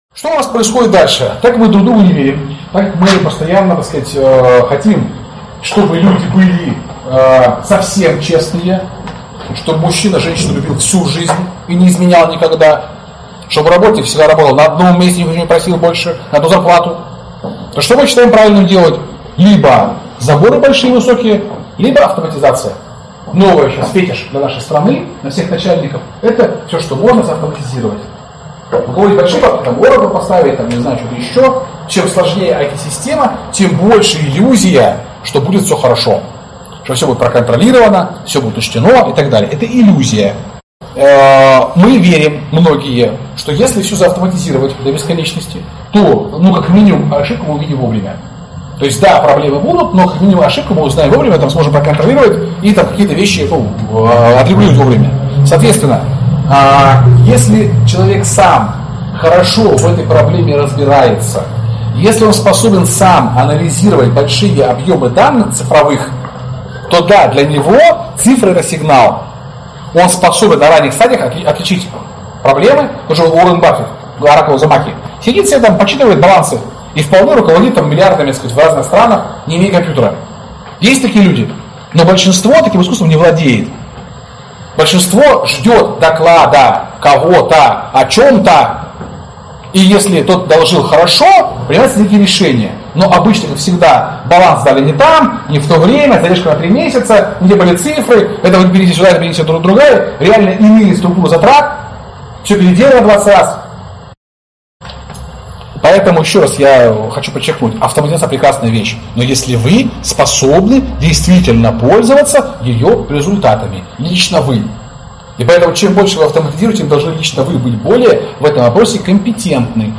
Аудиокнига Как теряется власть. Лекция 4 | Библиотека аудиокниг